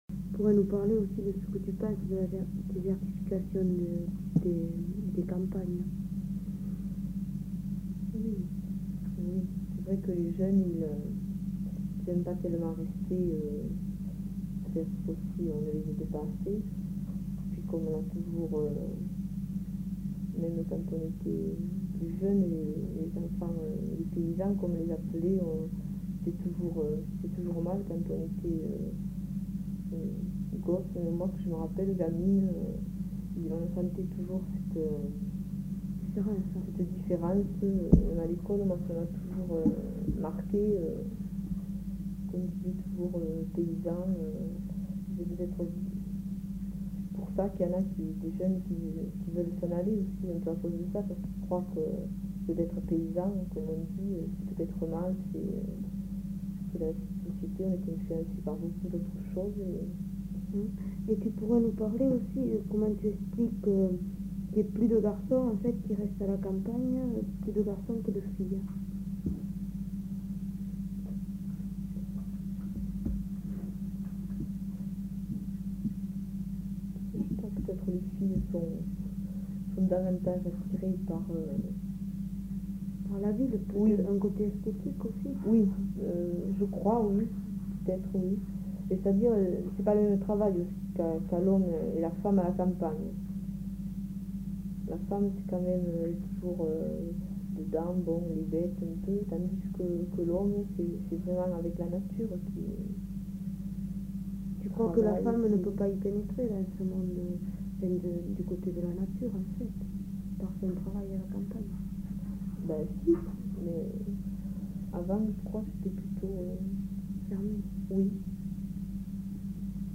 Aire culturelle : Savès
Lieu : Garravet
Genre : témoignage thématique